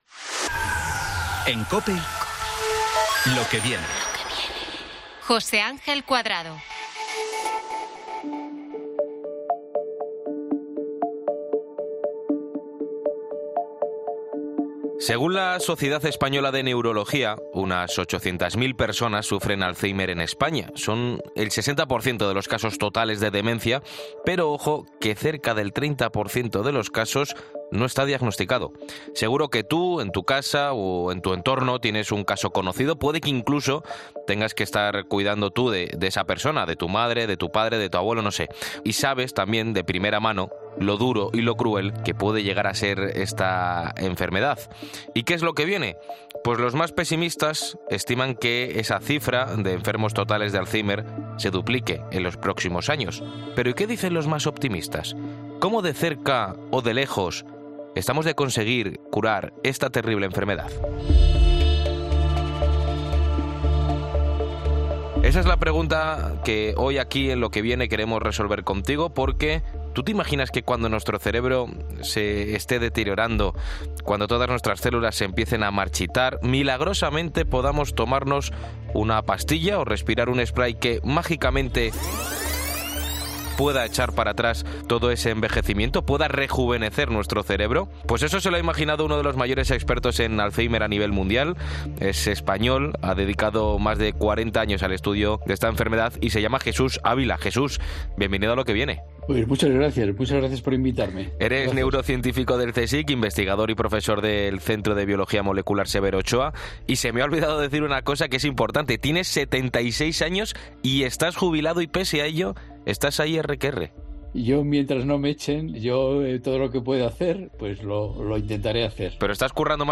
El experto ha pasado por los micrófonos de la Cadena COPE, para explicar cómo se le ocurrió la idea de poder rejuvenecer los cerebros gracias a la ciencia.